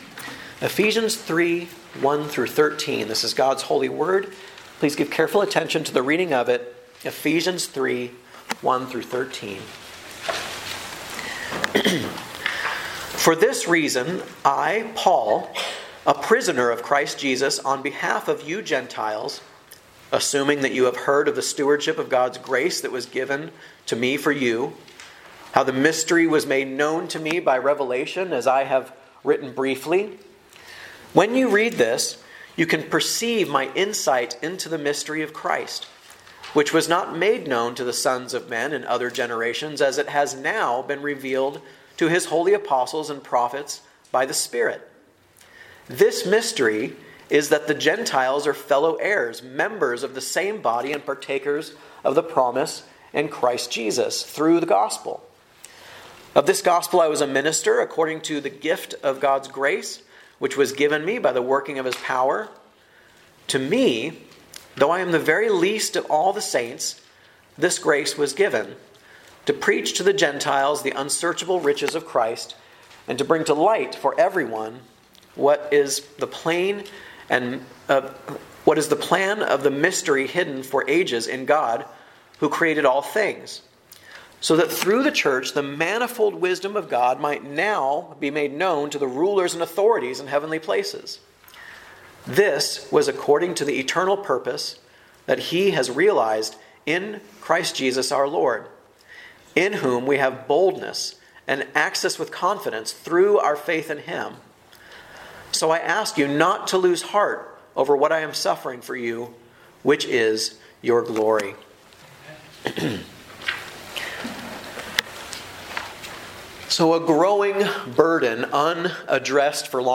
A message from the series "Guest Preacher." Prisoner by Design (vv1-5, 7-9) Grace stewarded in chains; suffering illuminates the gospel.